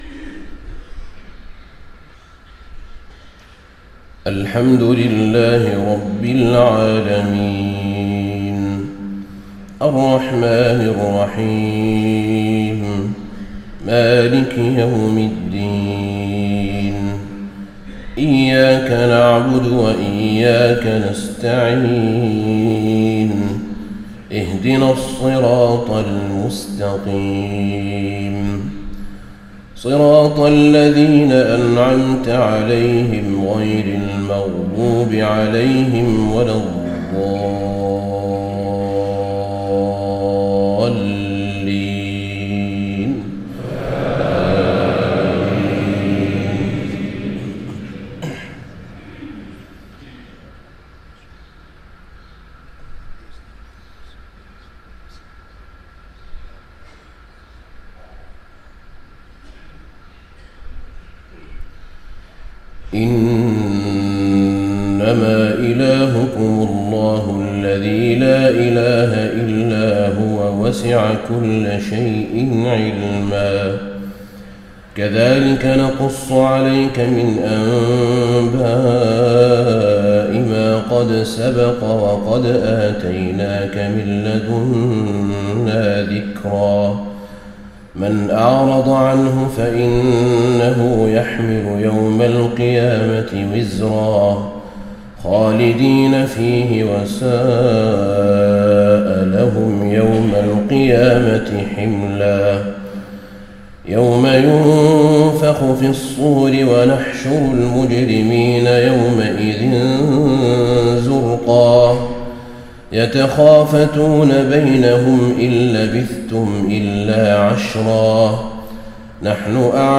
صلاة الفجر1-6-1435هـ خواتيم سورة طه 98-135 > 1435 🕌 > الفروض - تلاوات الحرمين